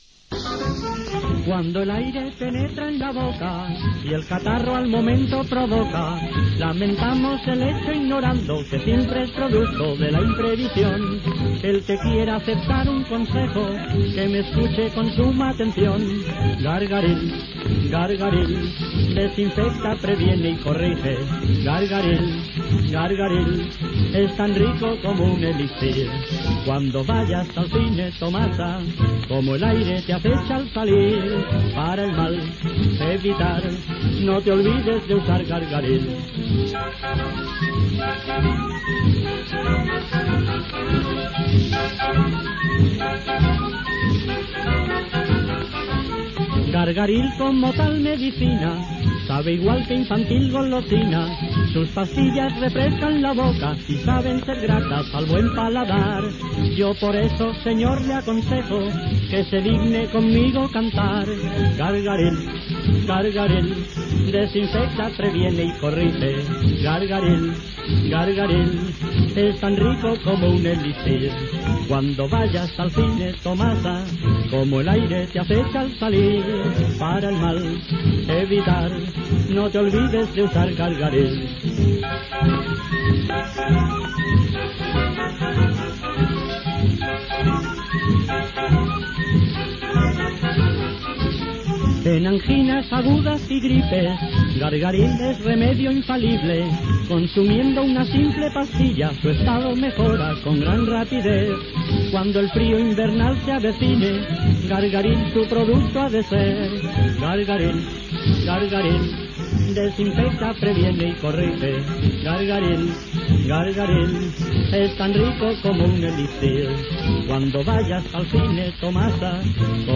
Cançó publicitària
a ritme de samba